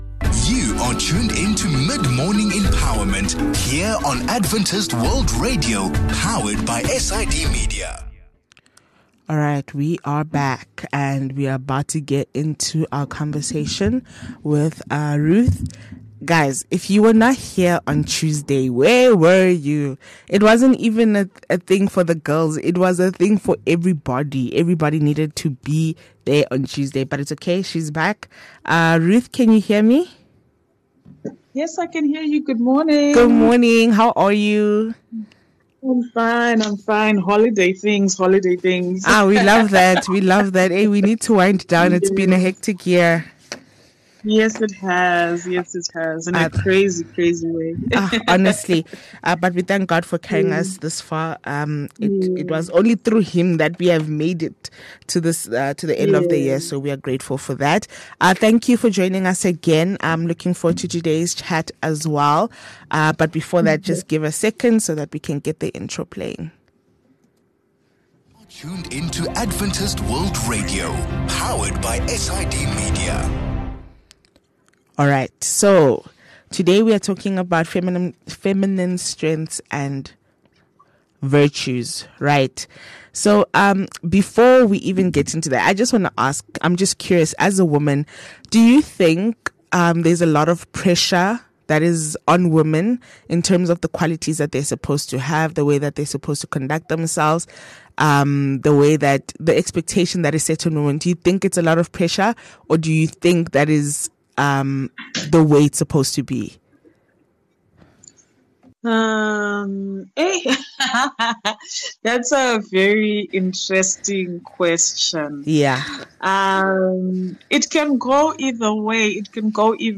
This discussion will illustrate a rich tapestry of feminine strengths and virtues, providing inspiration and guidance for Christian women.